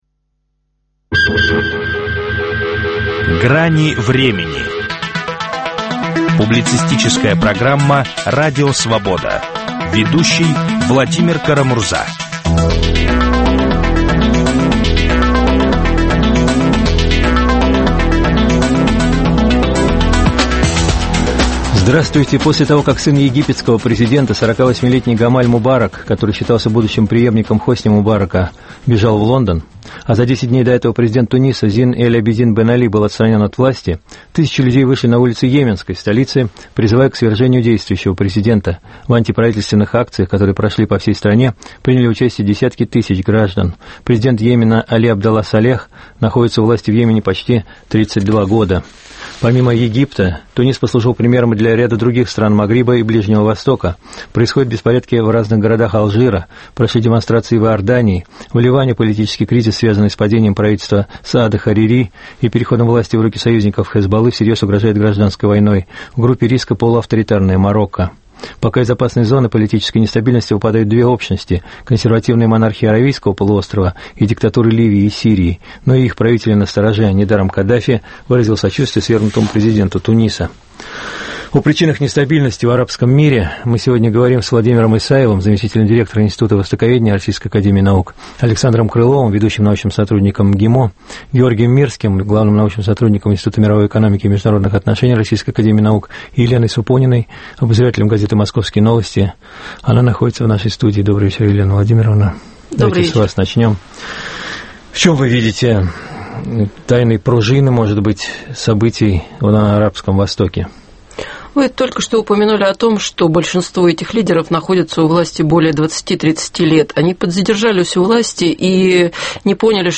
Грозит ли ситуация в Египте усилением нестабильности в арабском мире? Об этом говорят востоковеды и африканисты